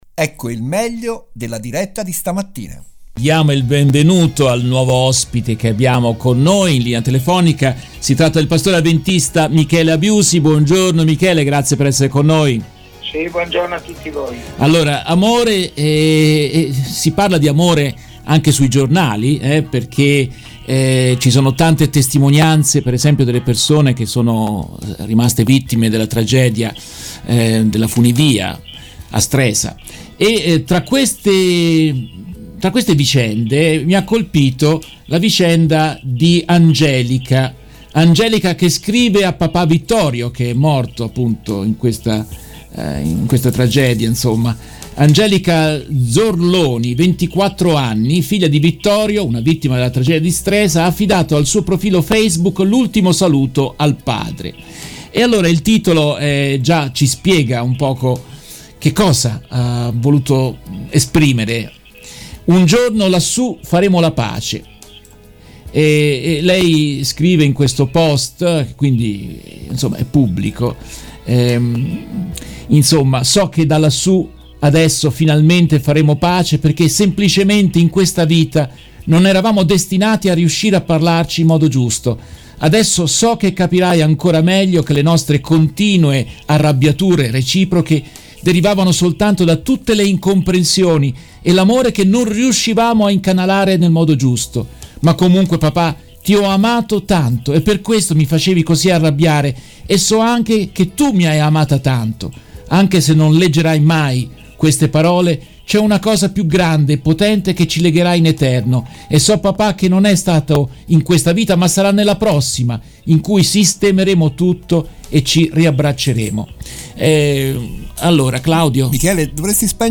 La trasmissione dedicata all'attualità su RVS